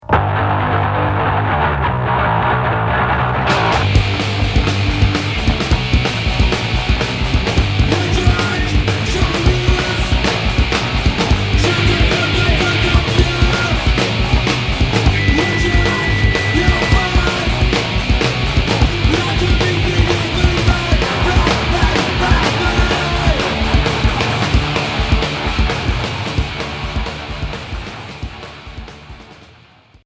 Hardcore Quatrième 45t